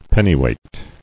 [pen·ny·weight]